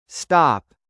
fart Sound Button: Unblocked Meme Soundboard
Play the iconic fart sound button for your meme soundboard!